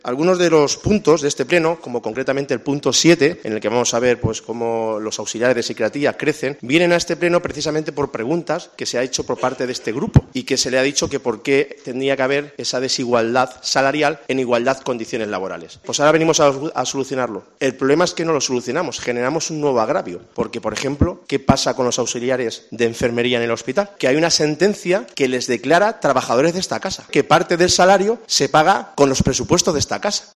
Adrián Fernández, portavoz PP Diputación Ciudad Real